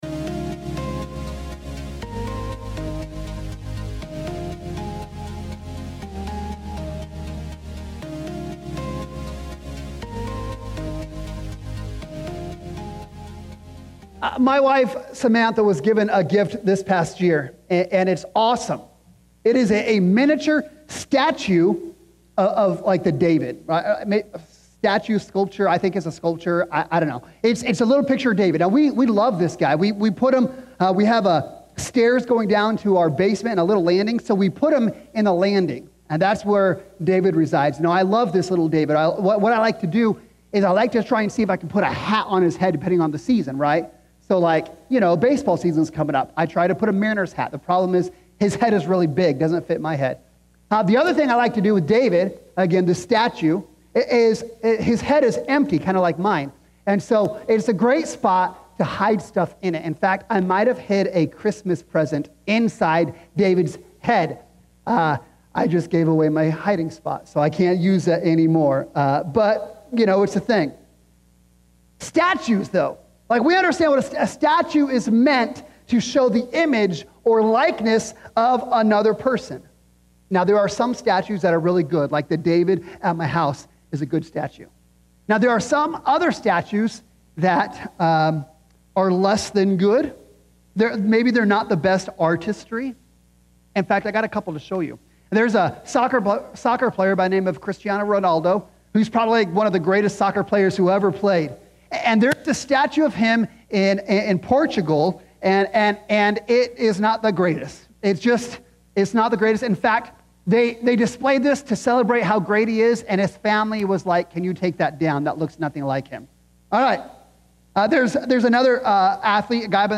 imago-dei-sermon.mp3